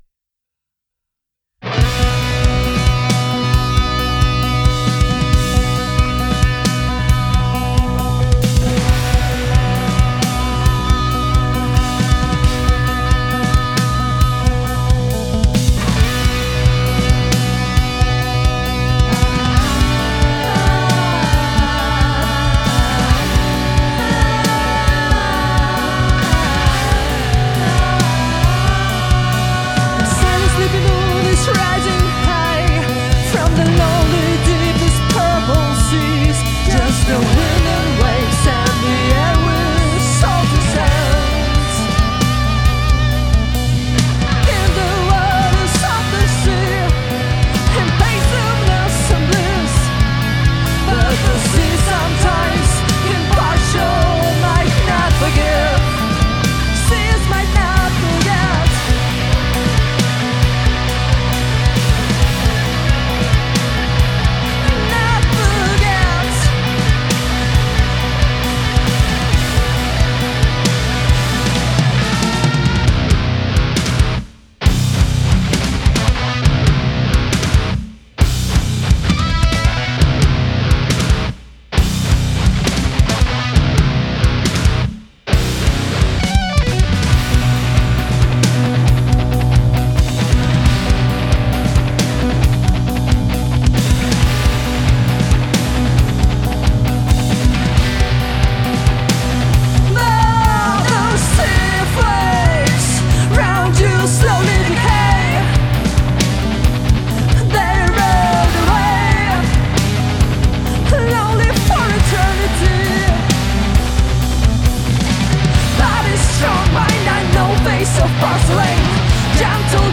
Guten Abend, Ich habe mal den Song zum Anlass genommen die Waves CLA MixHub Chanelstrips auf den Drumspuren zu probieren ohne irgendwelche Samples.
Bis auf einen "eternal" Hall in Vox habe ich nichts automatisiert.
- In der folgenden Passage nach dem Intro passt der Grundsound des Kits eigentlich schon ganz gut.